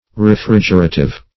Search Result for " refrigerative" : The Collaborative International Dictionary of English v.0.48: Refrigerative \Re*frig"er*a*tive\ (r?*fr?j"?r*?*t?v), a. [Cf. F. r['e]frig['e]ratif.]
refrigerative.mp3